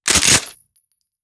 sfx_shotgun_reload_2.wav